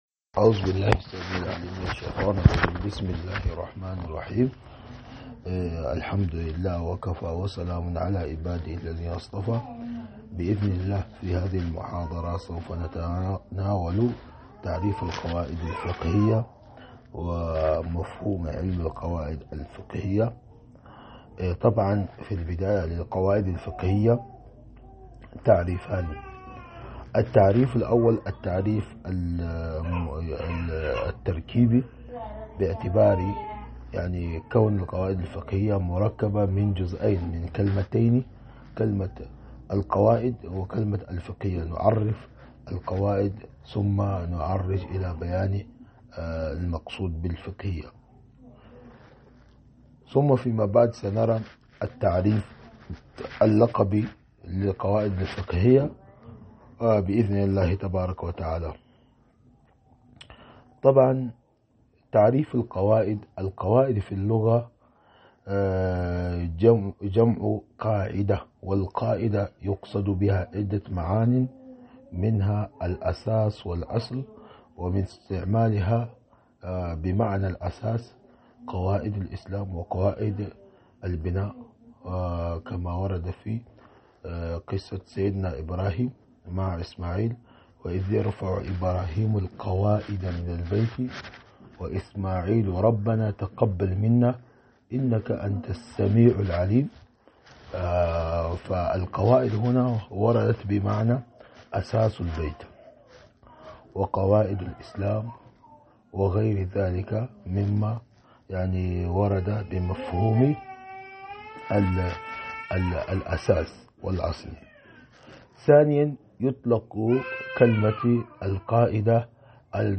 محاضرة مادة القواعد الفقهية 003